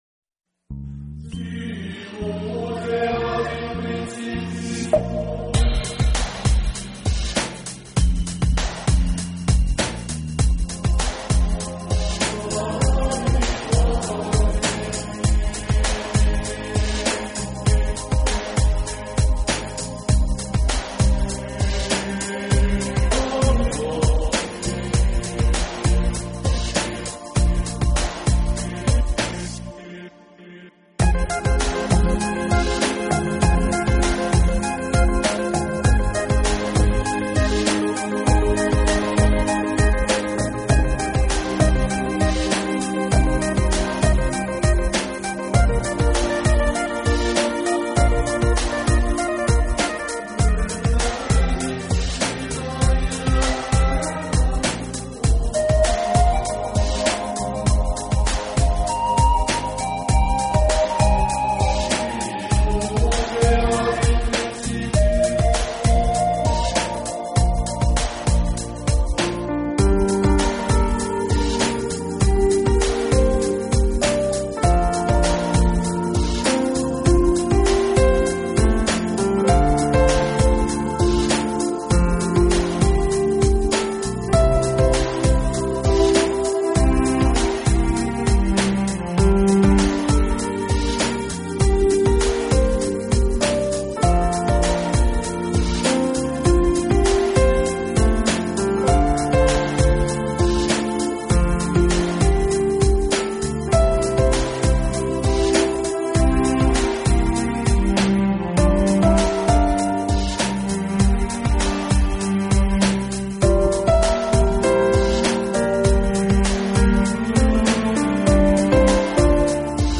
【新世纪音乐】
完美的驾驭着部落的吟唱和奇妙的节奏，创造出了独树一帜的风格。